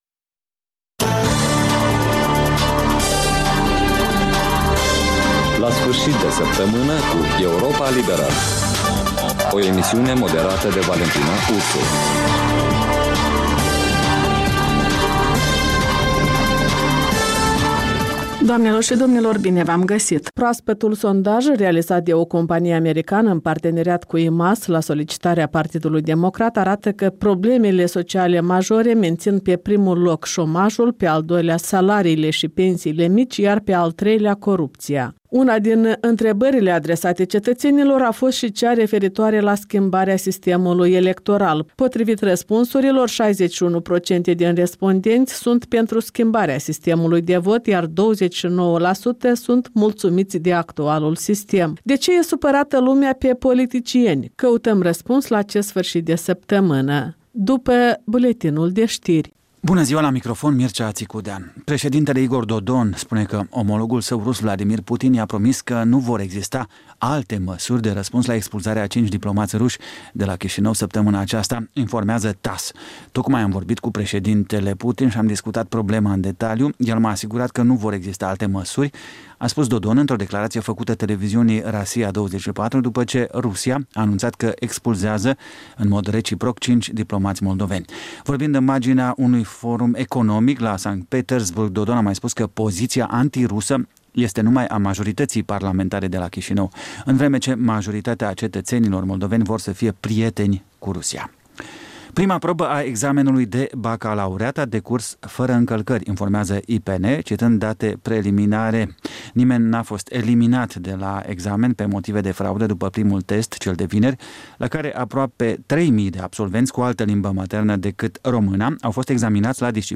reportaje, interviuri, voci din ţară despre una din temele de actualitate ale săptămînii.